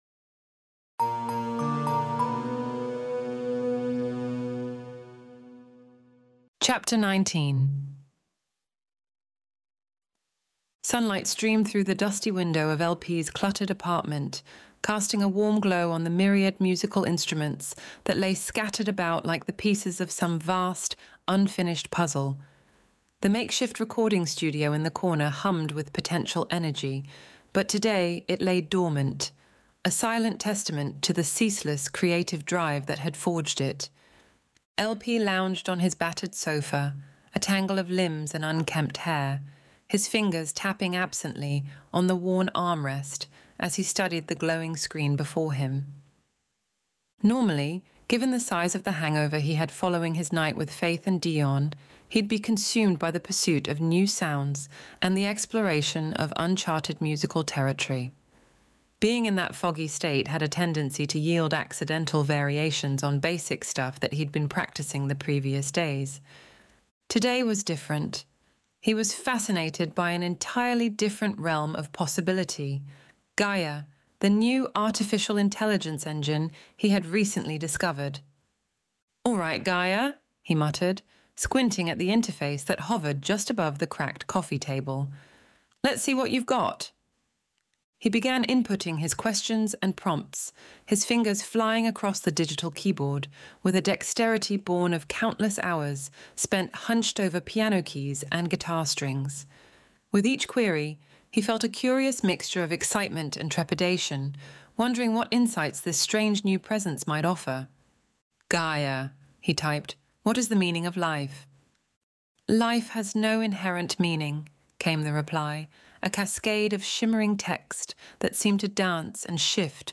Extinction Event Audiobook